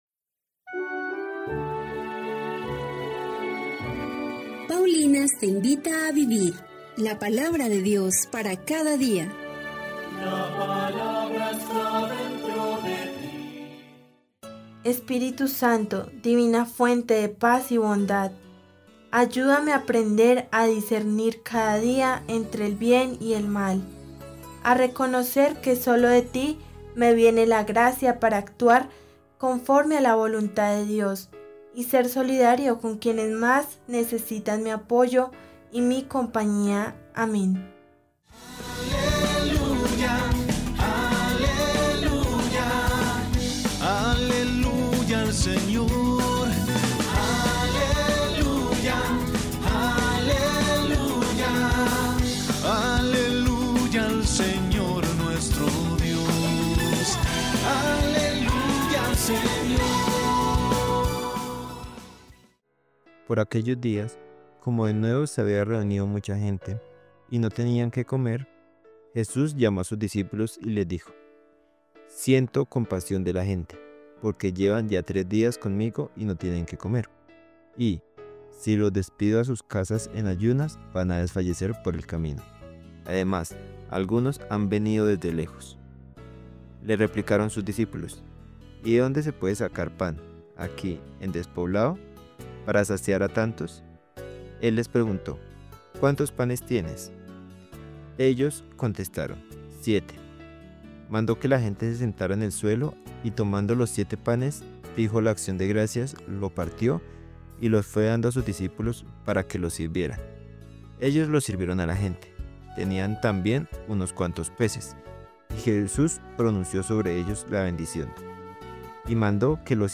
Lectura del libro del Deuteronomio 30, 15-20